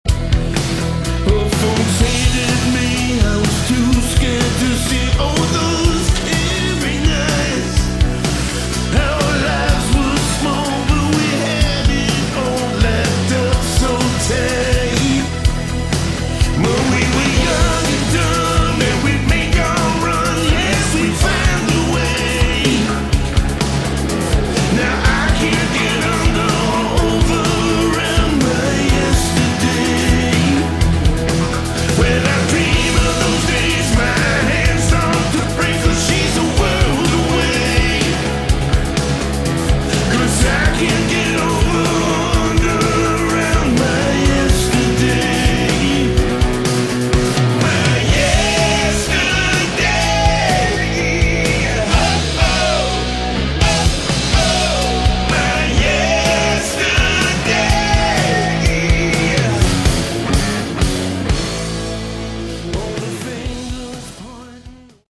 Category: AOR / Melodic Rock
lead vocals
guitars